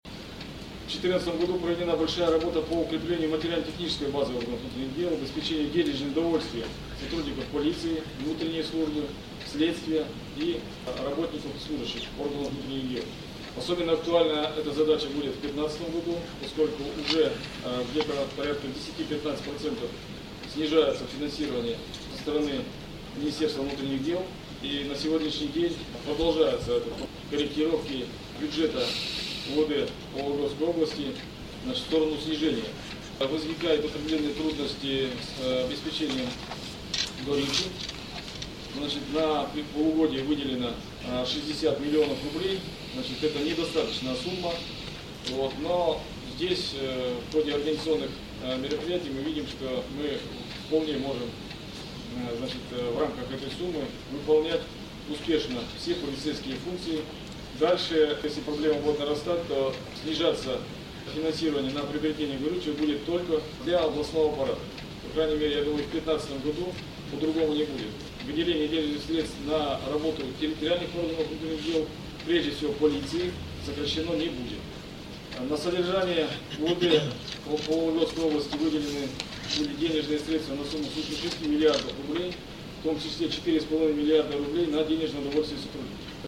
Эти данные главный вологодский полицейский озвучил на коллегии областного УМВД, которая состоялась в четверг, 22 января.
Виталий Федотов рассказывает о сокращении финансирования УМВД